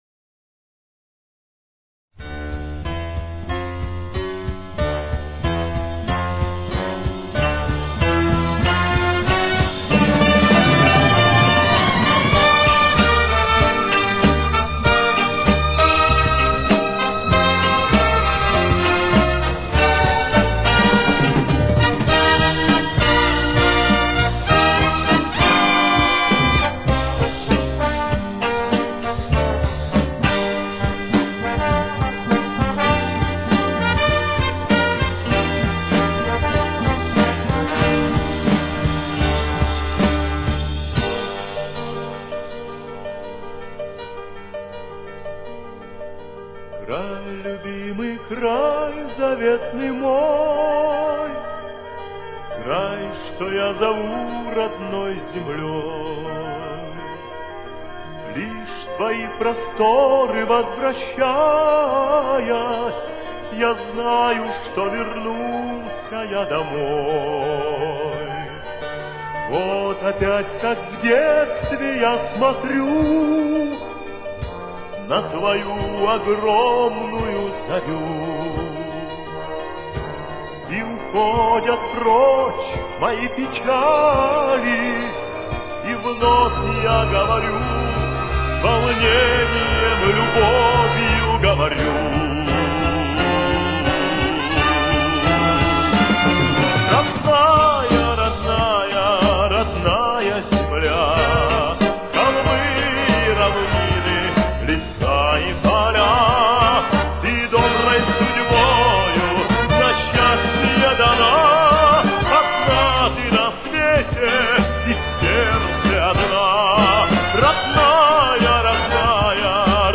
Темп: 96.